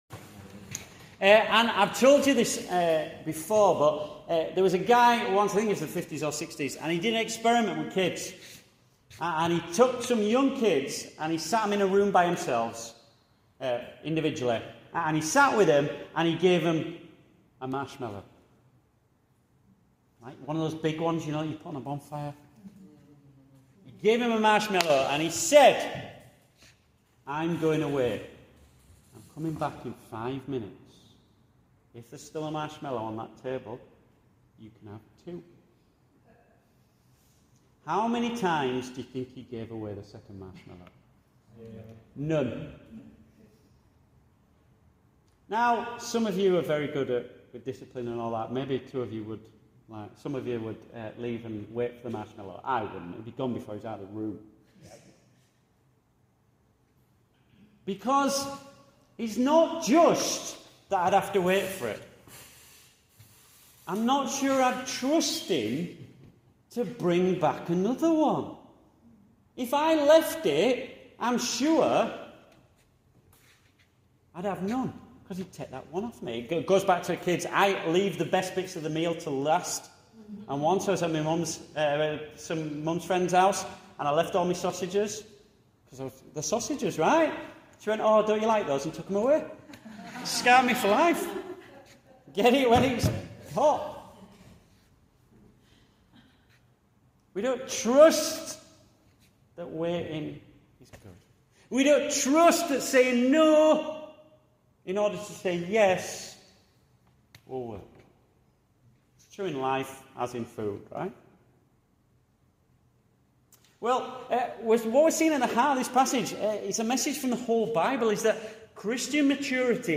Returning Home Passage: Leviticus 21-22 Service Type: Morning Service « Who lives blamelessly?